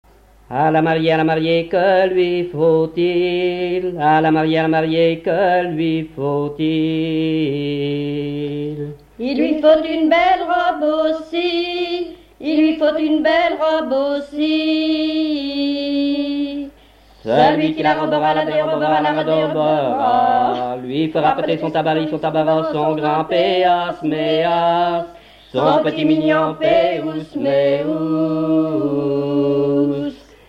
Genre énumérative
chansons traditionnelles et populaires
Pièce musicale inédite